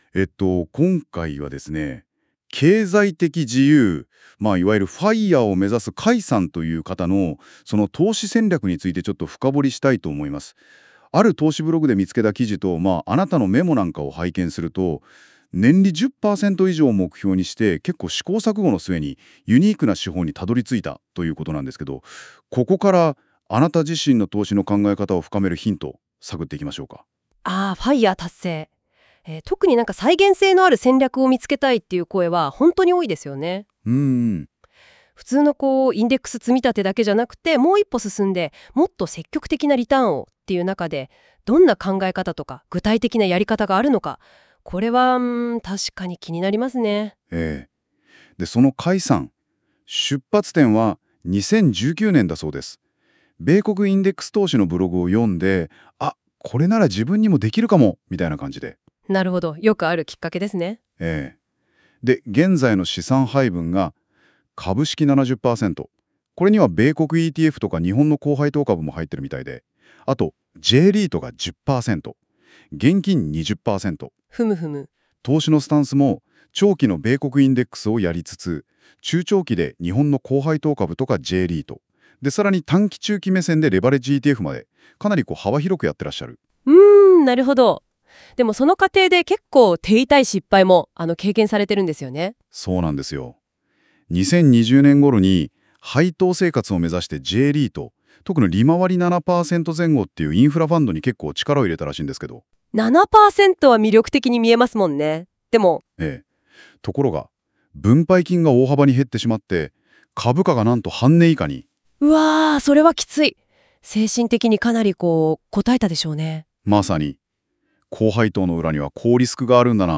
音声で解説しました！